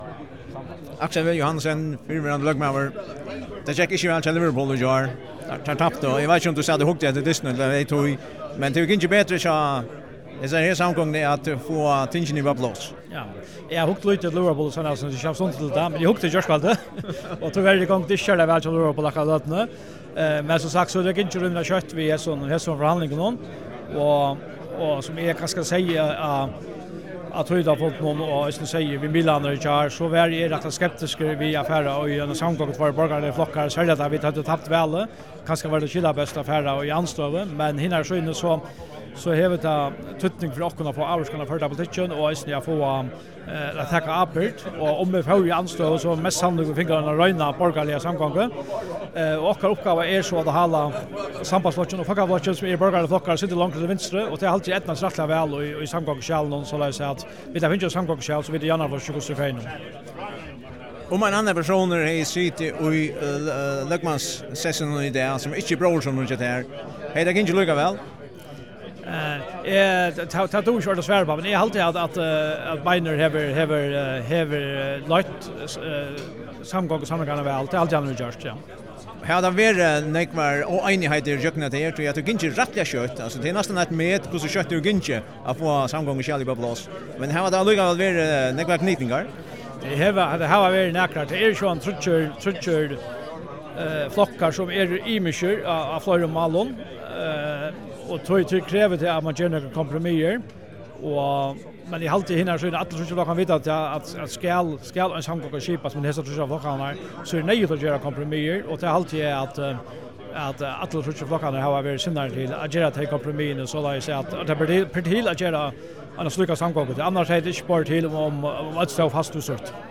Samrøðan er gjørd eftir tíðindafundin í Løgtinginum har samgonguskjalið bleiv undirskrivað.